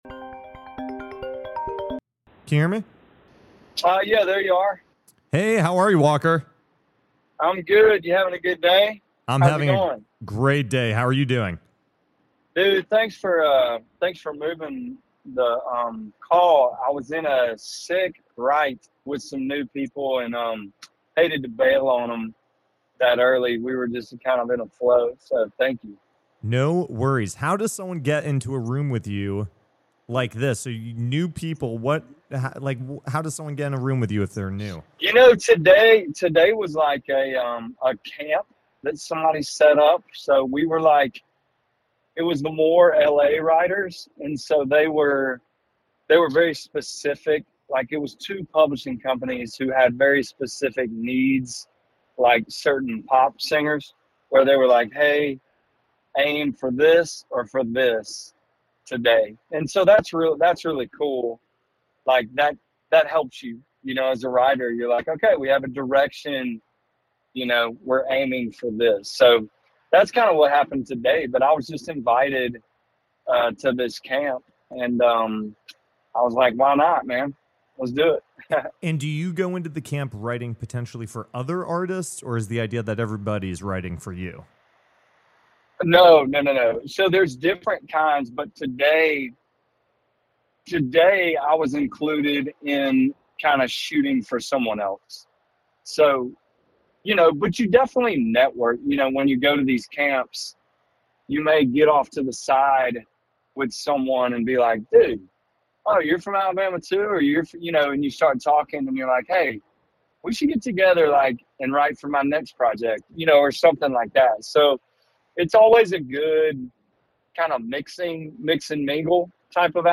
A phone call with Walker Hayes (; 25 Aug 2025) | Padverb
Mr. Applebee's himself Walker Hayes calls into the show this week to discuss his new album and how he got Kane Brown on a feature.